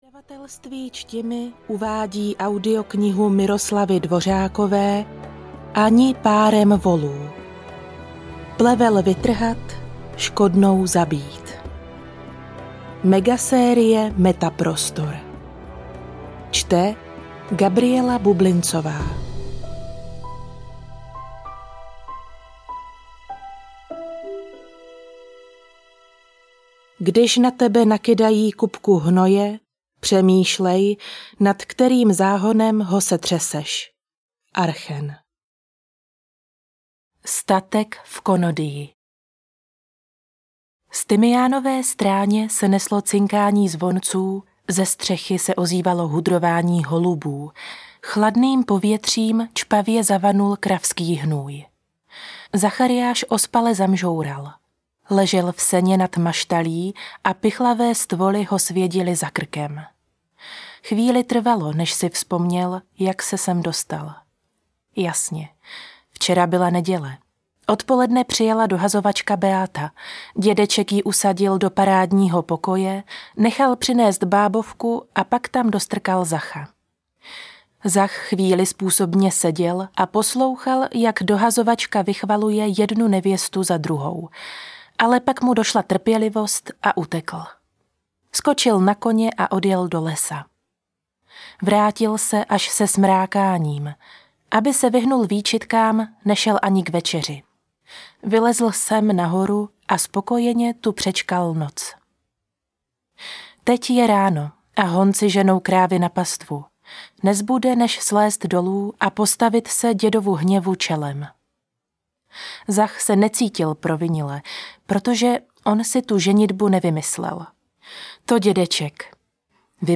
Ani párem volů audiokniha
Ukázka z knihy
ani-parem-volu-audiokniha